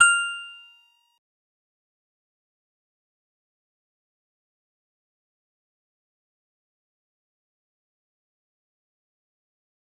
G_Musicbox-F7-mf.wav